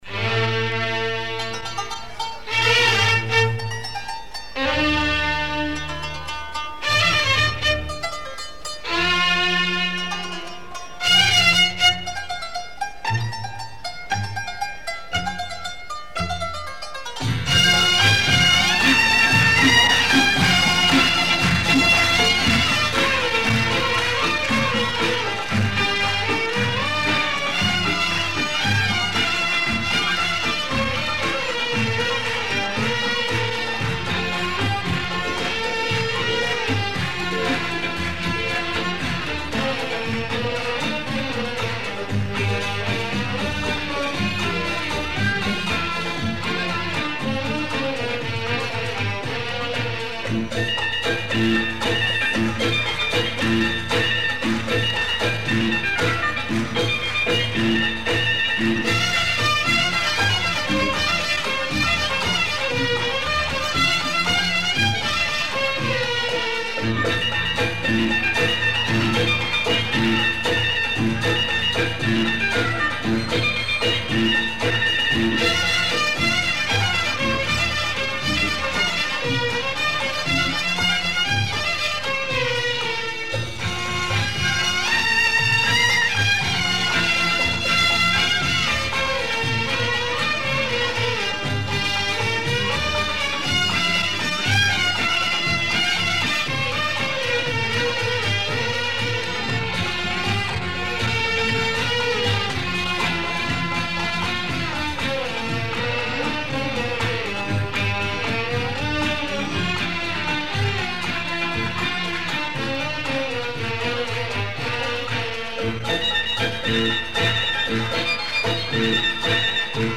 Killer oriental beats